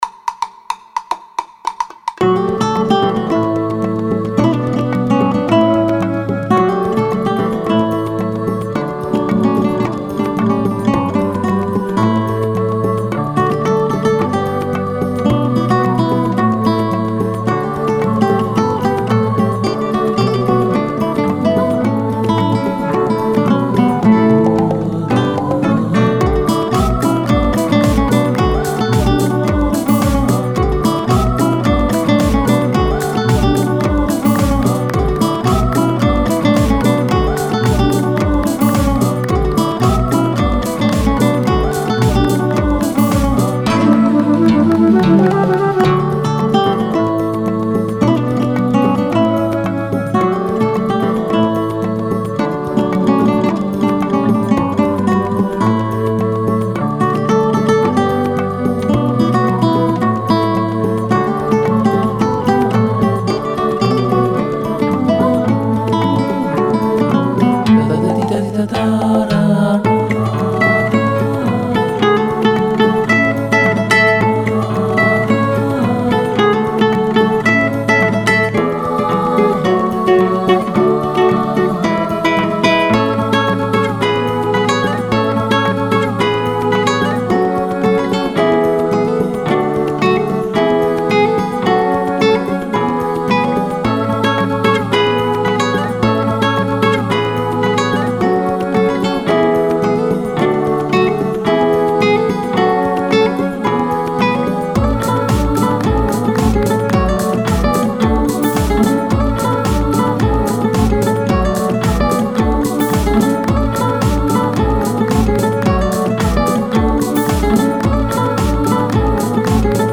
قطعه بیکلام
کارشناس موسیقی-آهنگساز-نوازنده ویولین، گینار و پیانو